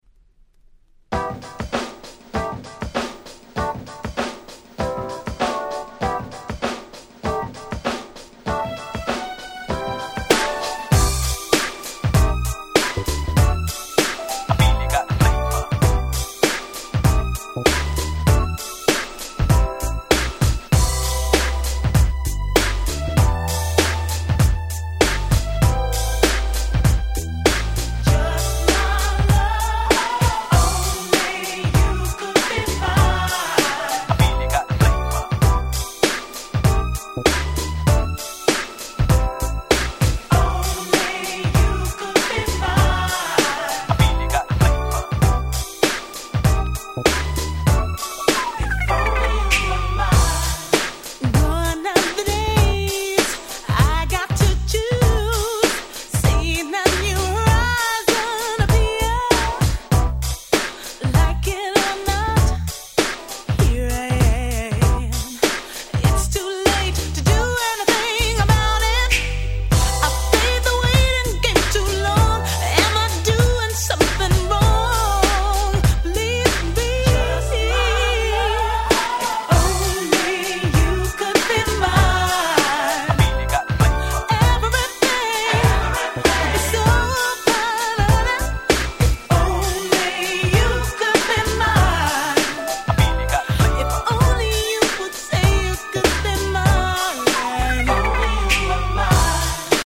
95' Nice UK R&B !!